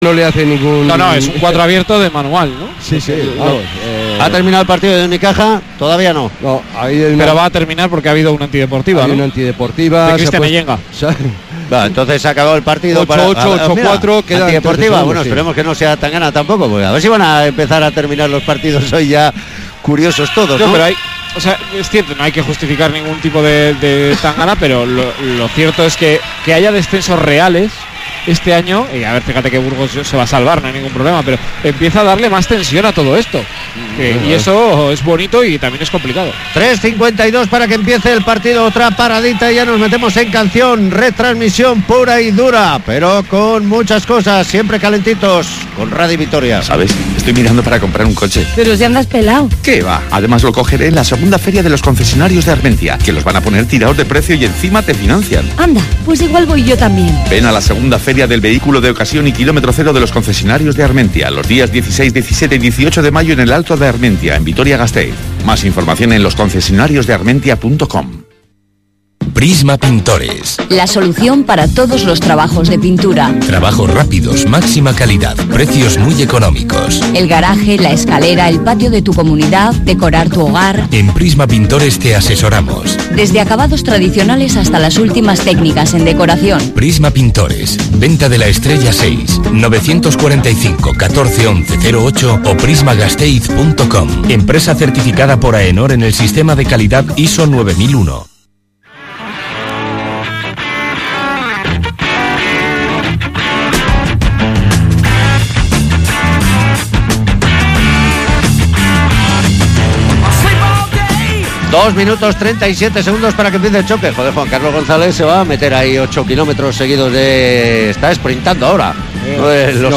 Gipuzkoa basket-Baskonia jornada 29 liga ACB 2018-19 retransmisión Radio Vitoria